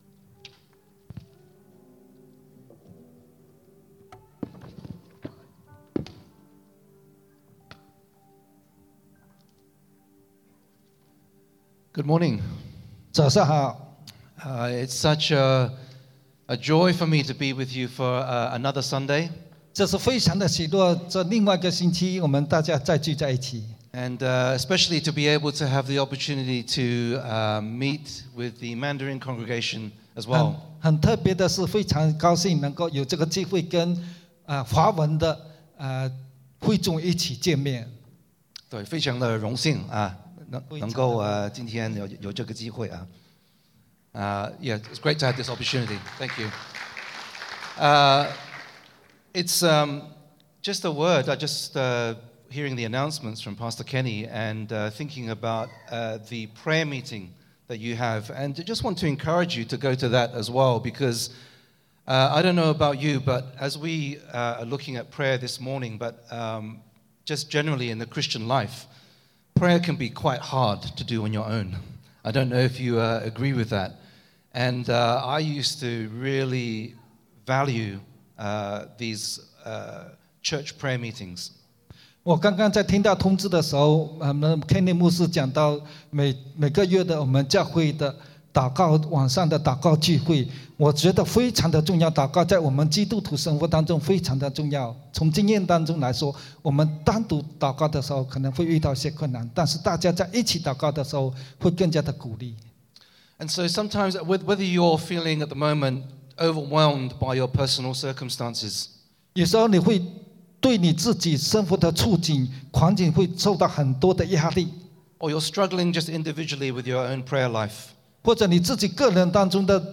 English Sermons | Casey Life International Church (CLIC)
English Worship Service - 23 July 2023